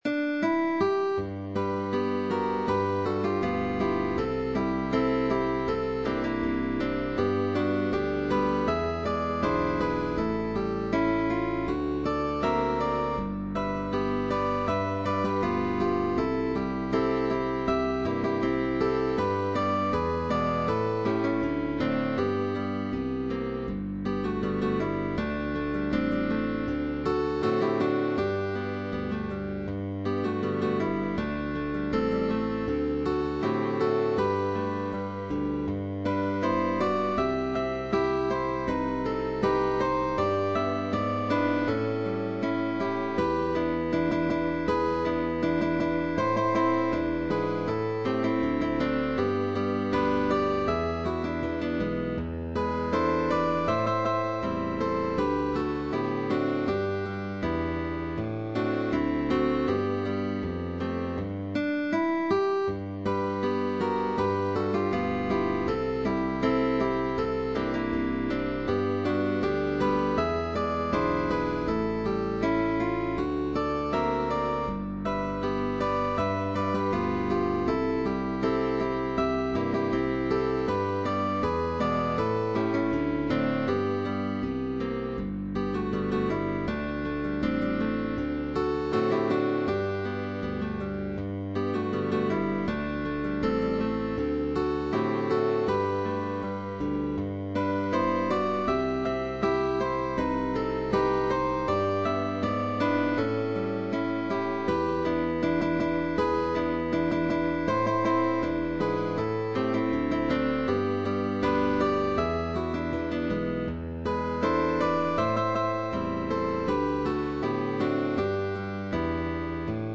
GUITAR TRIO
jazz song
Arpeggios and chords.